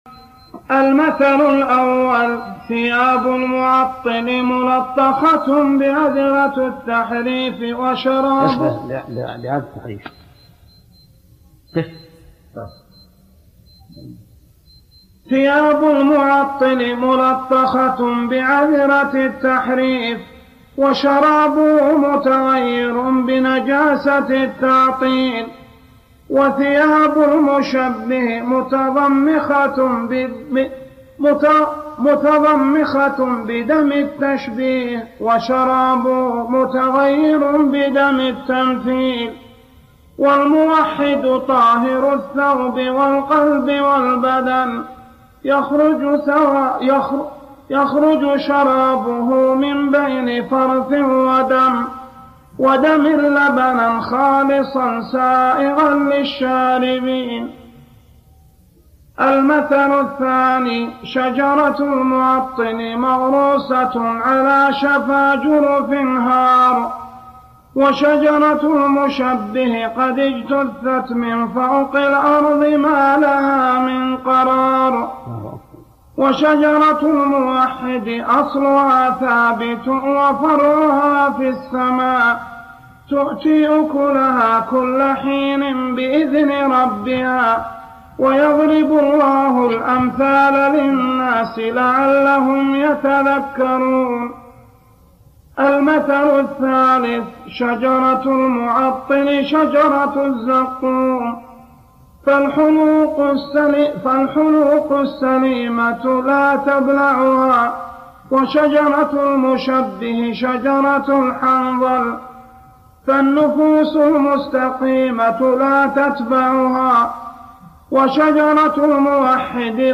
الأمثلة العشرة التي ضربها ابن القيم للمعطل والمشبه والموحد (قراءة للمتن) - ابن عثيمين